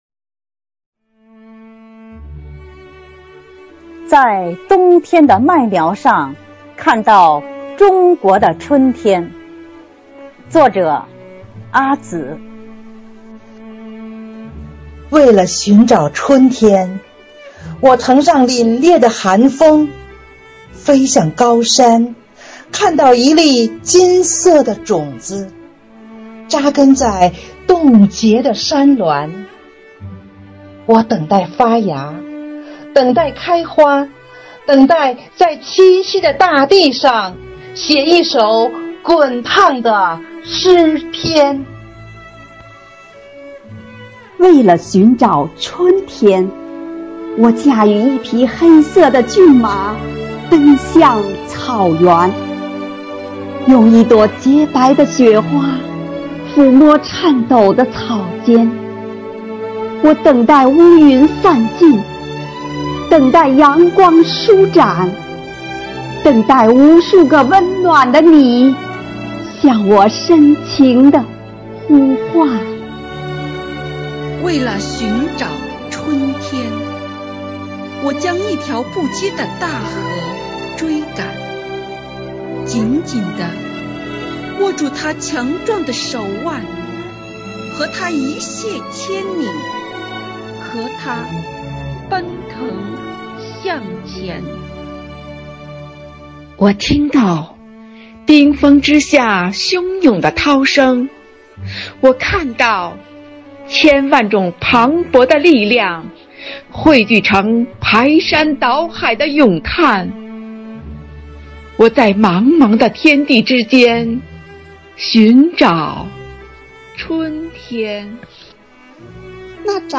“四月，我们和春天在一起”主题云朗诵会
合诵《在冬天的麦苗上看到中国的春天》
生活好课堂幸福志愿者中华诗韵朗读服务（支）队
《在冬天的麦苗上看到中国的春天》合诵：中华诗韵朗读支队.mp3